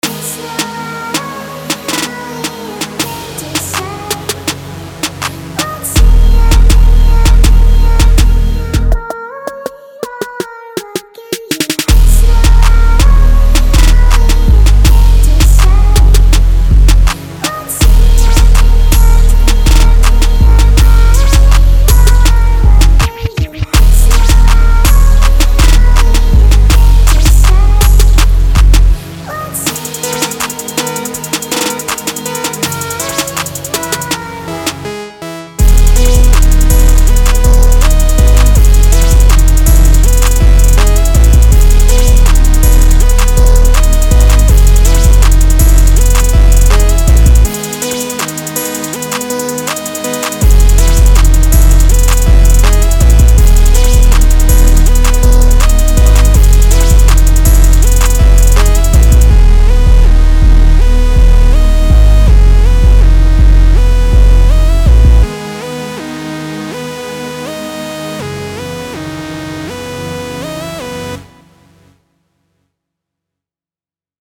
Жанр: edm, rage, hiphop